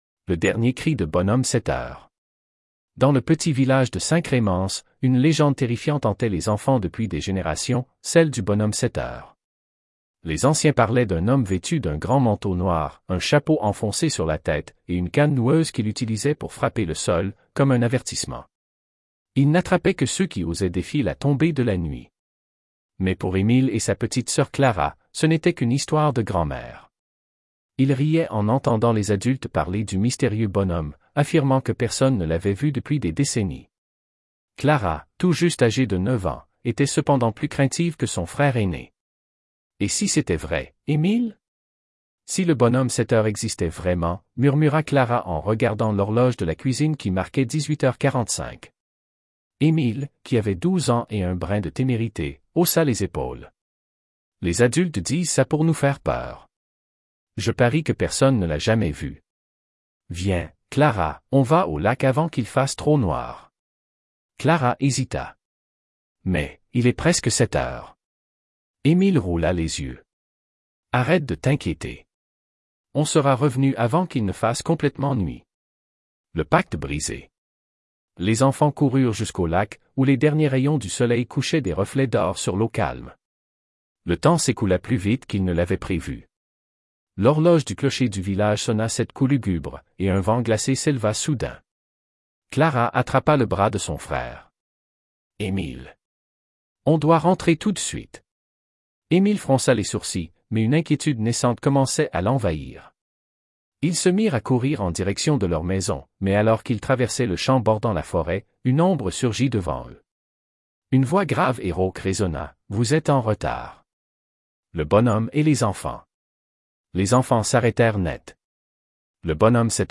Merci IA